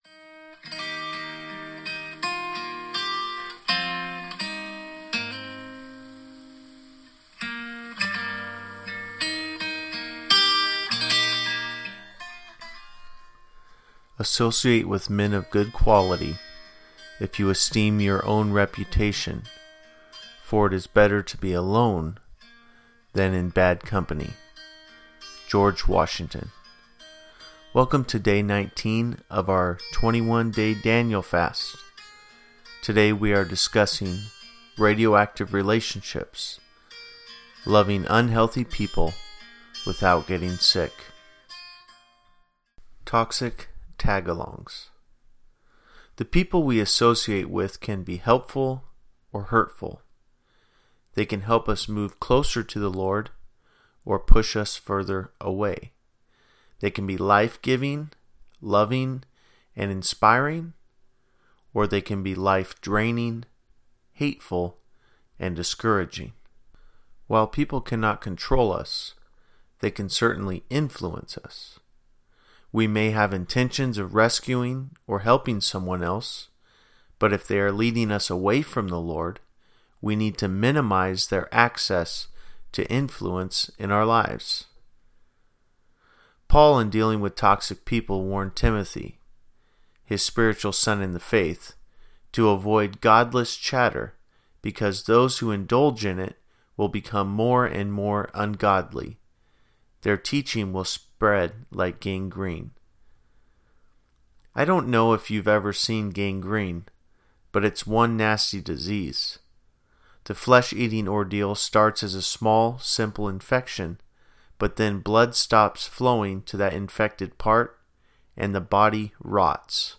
January 19, 2019 Radioactive Relationships: Loving Unhealthy People Without Getting Sick Below you will find an audio of the devotion being read for you, followed by the written devotion.